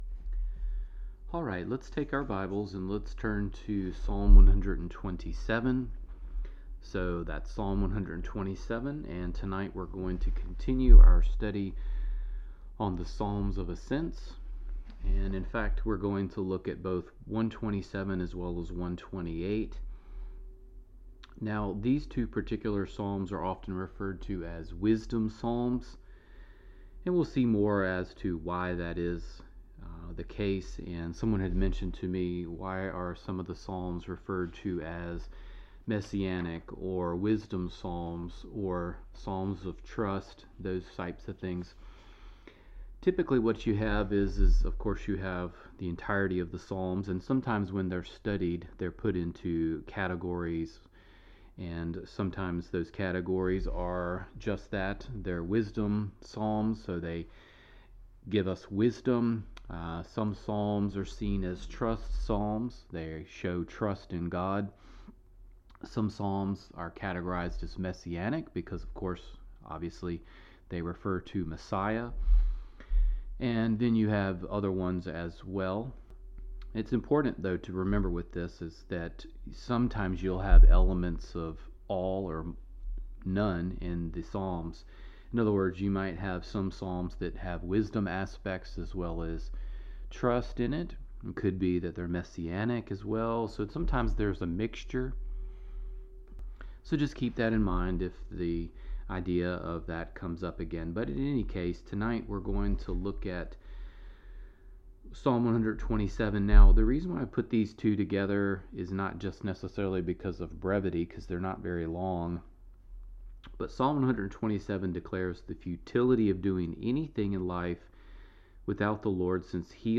Download Download Psalm 127 & 128 (The Psalms of Ascents) Wed. Night Bible Study Matthew 23:37-39 "The King's Lament" Wed.